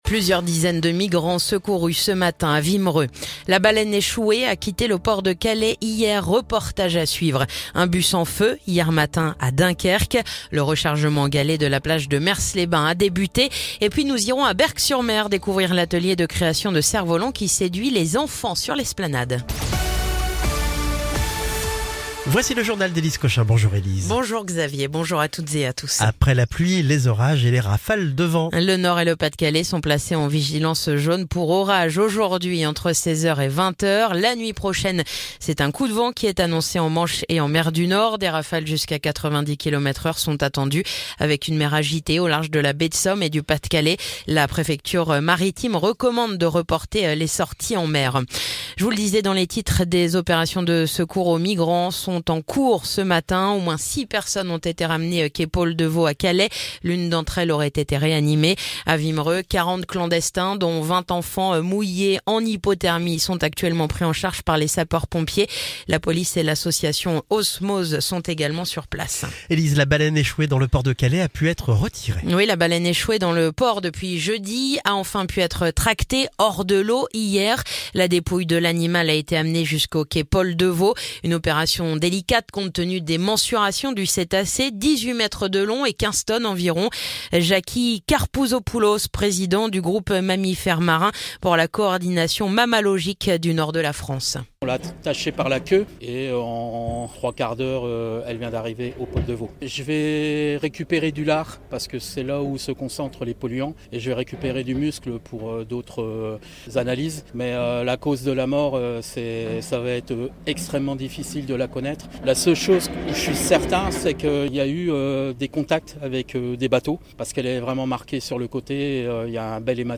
Le journal du mardi 15 avril